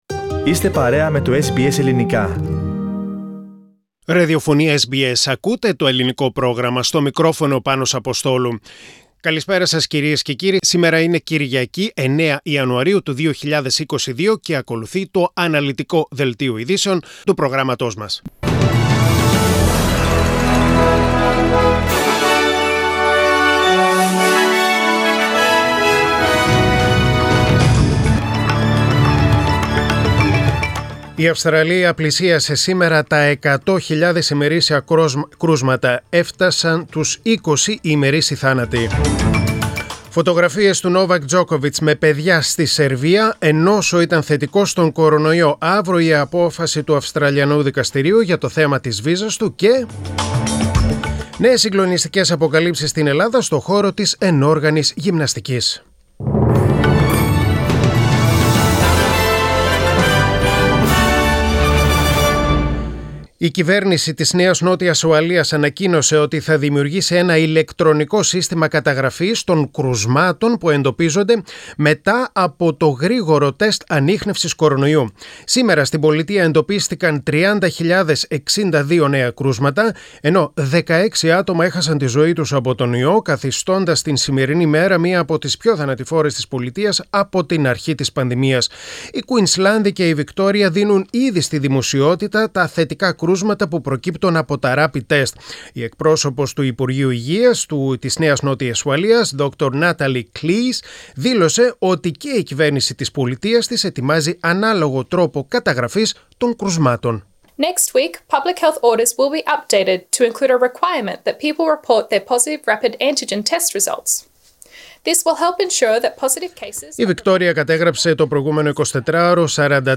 News in Greek: Sunday 9.1.2022